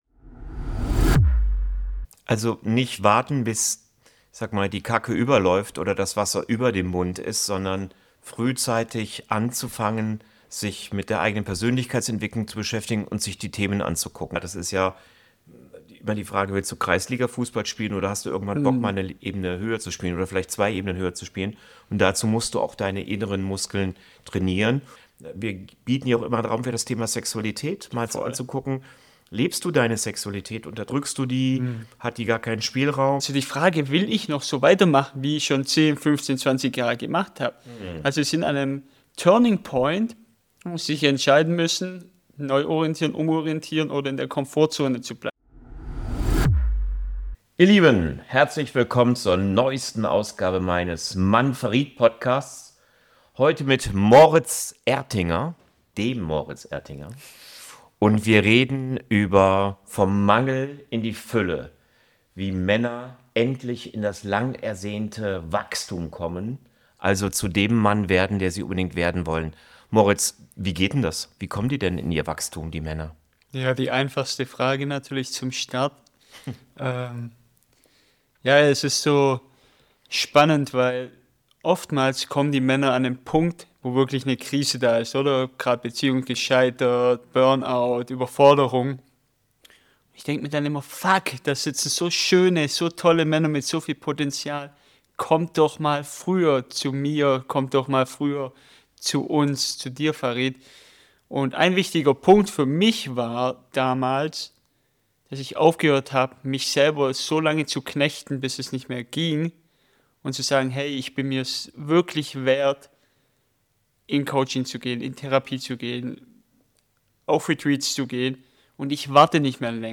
Ein ehrliches Gespräch über Männlichkeit, persönliche Entwicklung, Beziehungen, Sexualität, Karriere und Überforderung – und darüber, wie Männer durch Grow Beyond wieder Zugang zu ihrer inneren Stärke finden.